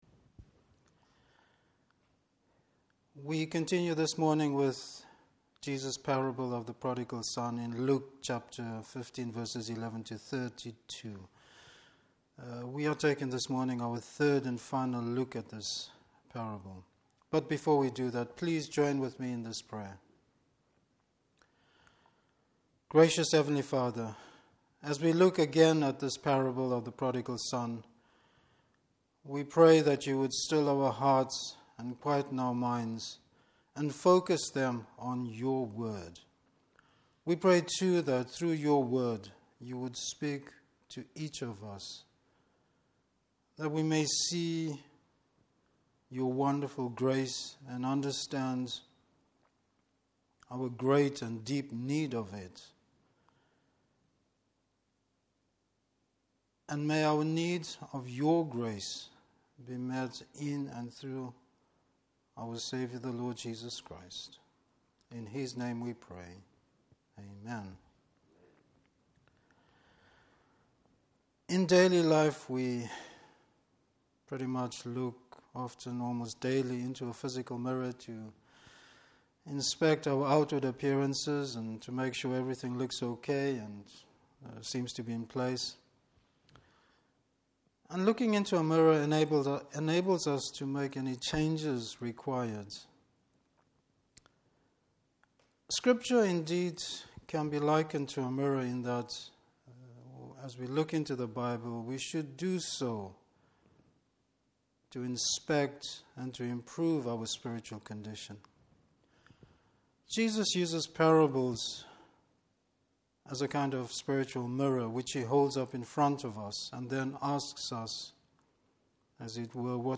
Service Type: Morning Service Bible Text: Luke 15:11-32.